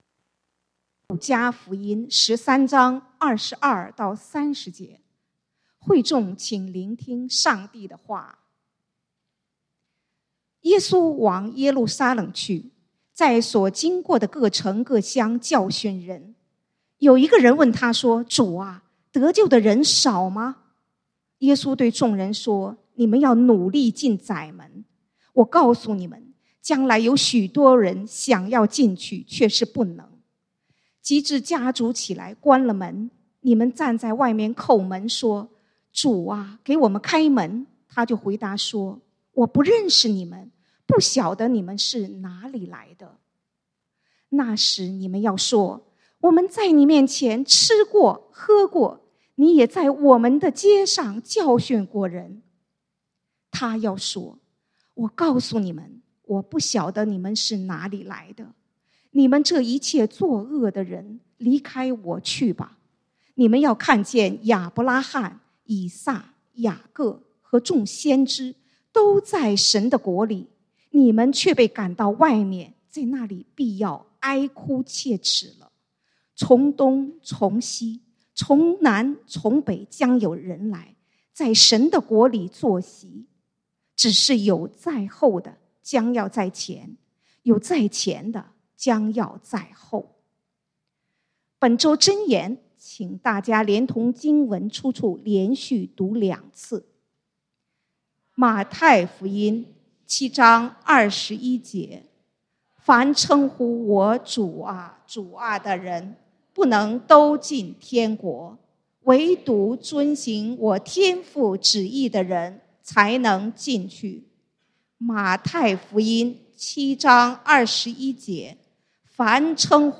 講道經文：路加福音 Luke 13:22-30 本週箴言：馬太福音 Matthew 7:21 耶穌說：「凡稱呼我『主啊，主啊』的人不能都進天國； 惟獨遵行我天父旨意的人才能進去。」